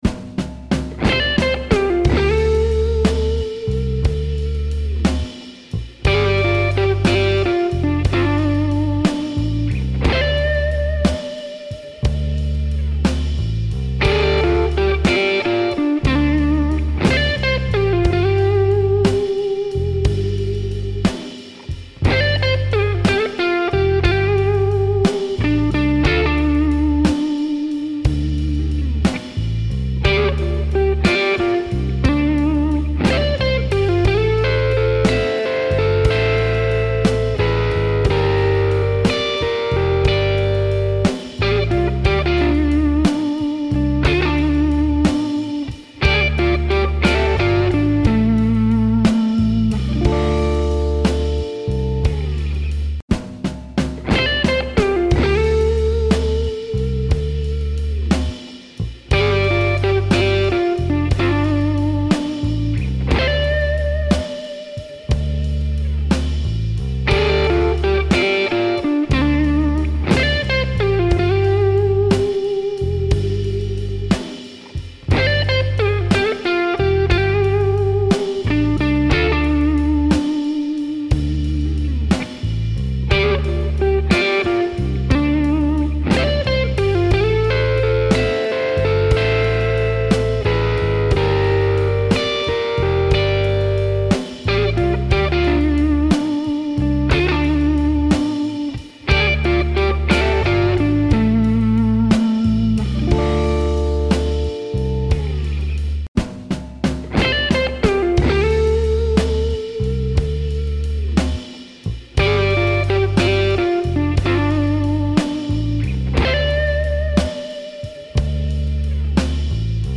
Intermediate to Advanced Rhythm and Soloing Techniques for Electric Blues!